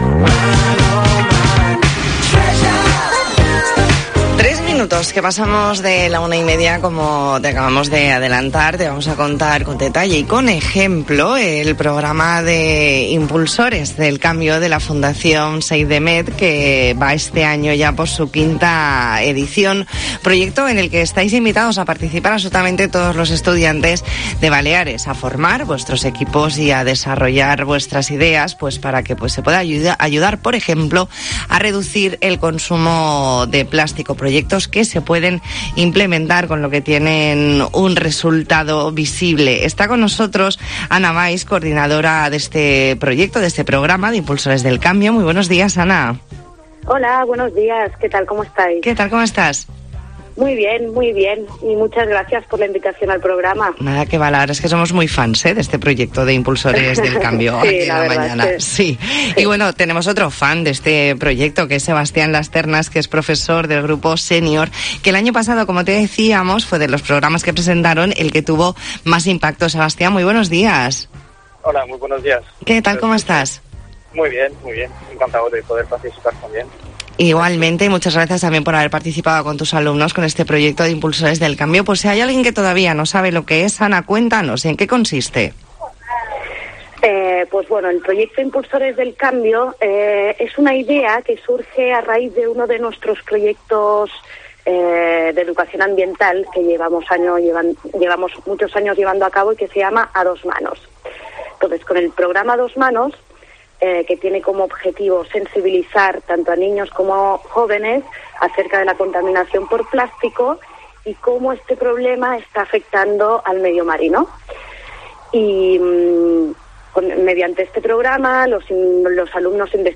Entrevista en La Mañana en COPE Más Mallorca, martes 18 de enero de 2022.